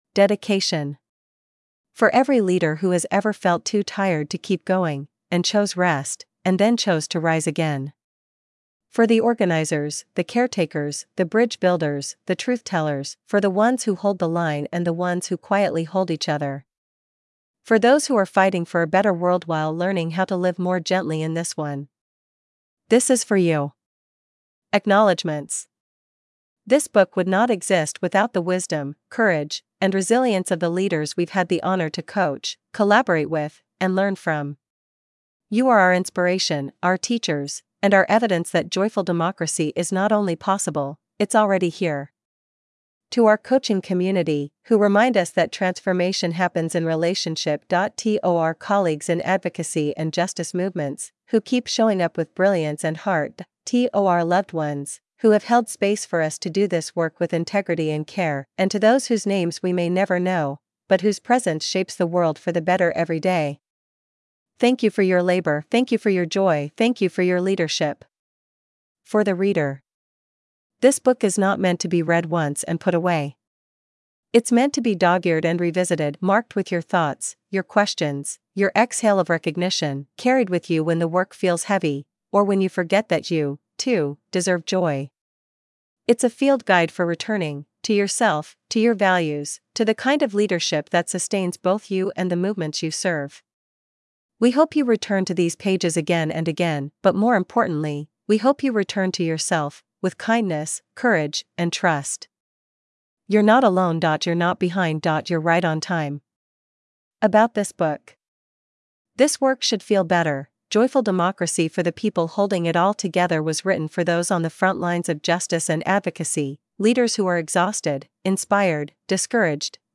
Escucha la versión narrada por IA GRATIS en formato MP3